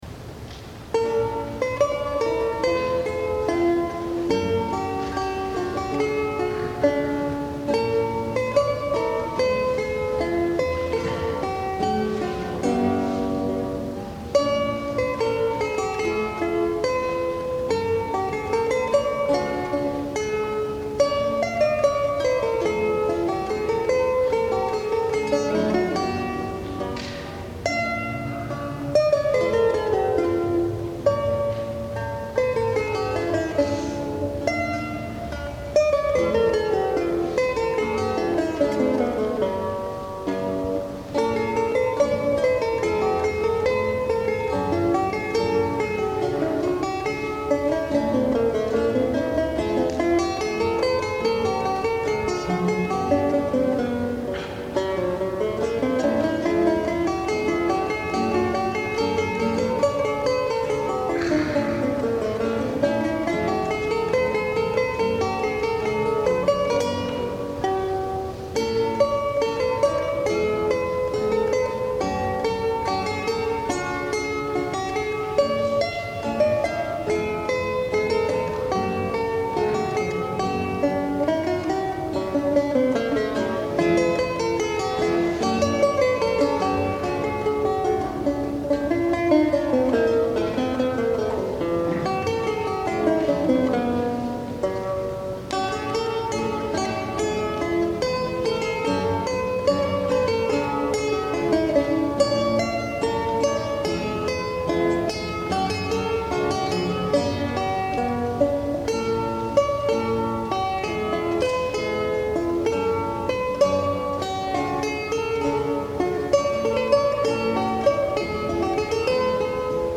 lute